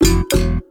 07382 bassconga denied ding
access cancel conga denied deny drum interface notification sound effect free sound royalty free Sound Effects